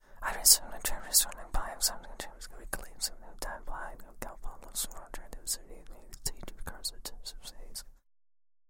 На этой странице собраны разнообразные звуки шепота: от загадочного женского до мужского нашептывания.
Мужчина тихо шепчет